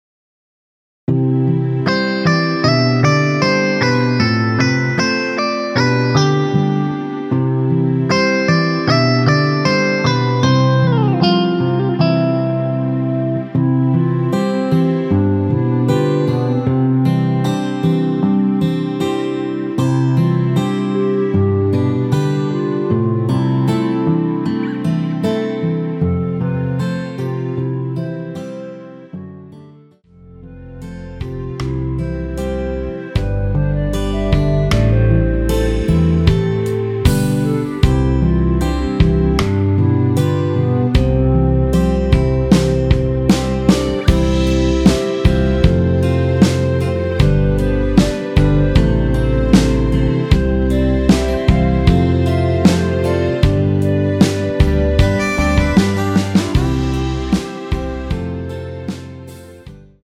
원키에서(-1)내린 (1절앞+후렴)으로 진행되는 멜로디 포함된 MR입니다.(미리듣기 확인)
◈ 곡명 옆 (-1)은 반음 내림, (+1)은 반음 올림 입니다.
멜로디 MR이라고 합니다.
앞부분30초, 뒷부분30초씩 편집해서 올려 드리고 있습니다.
중간에 음이 끈어지고 다시 나오는 이유는